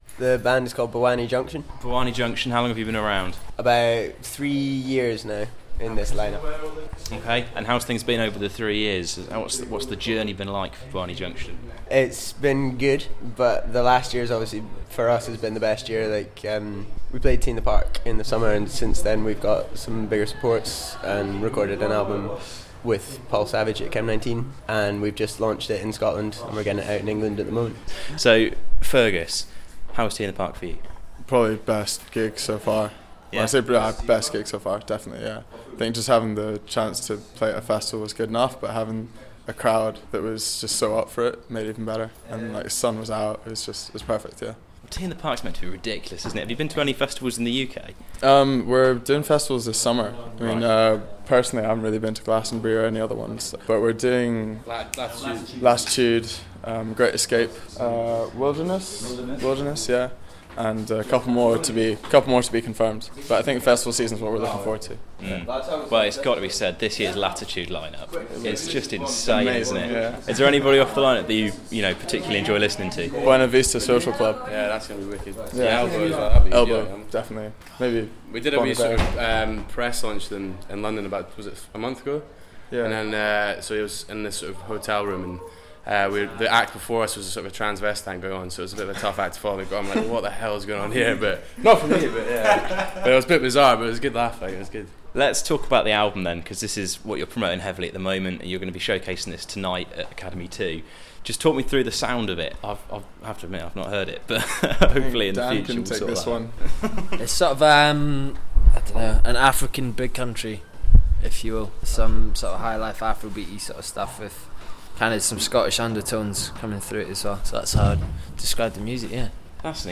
Edinburgh Afro-Scottish indie outfit Bwani Junction bumped into us at Manchester Academy, so we rolled the tape and had a bit of a conversation.